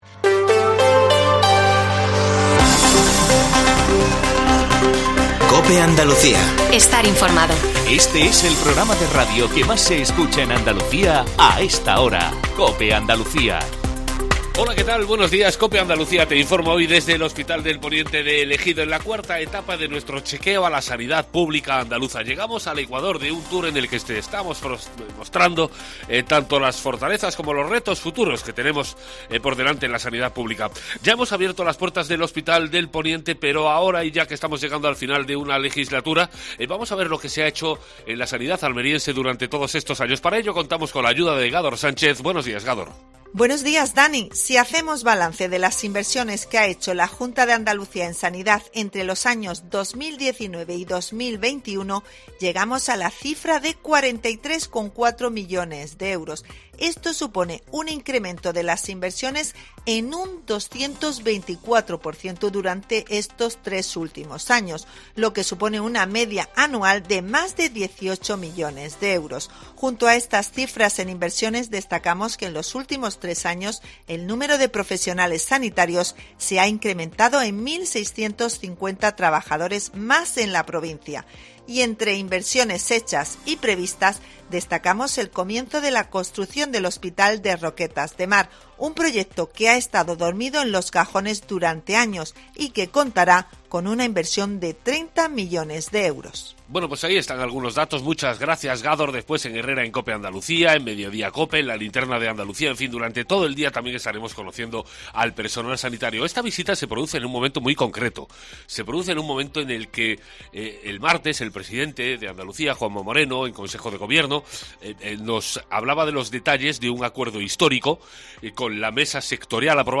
Herrera en COPE Andalucía 07.50 – 21 de abril - Desde el Hospital de Poniente en Almería